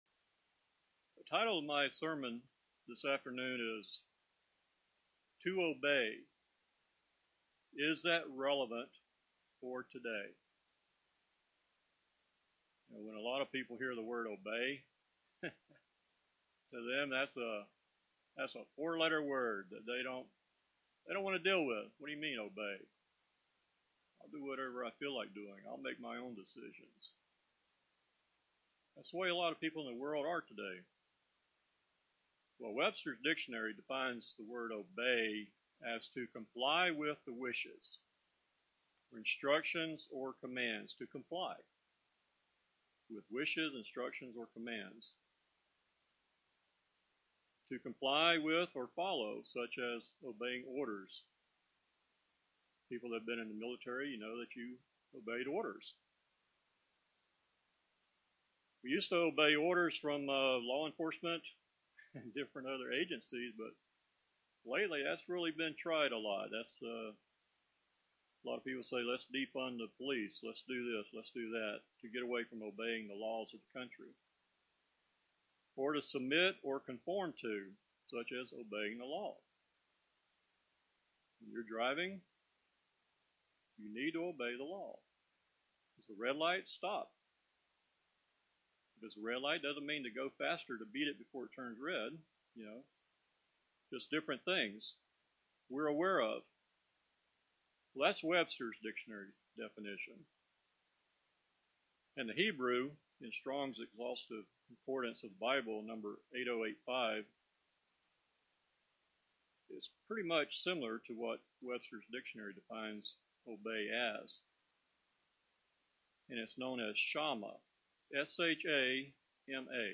Given in Tampa, FL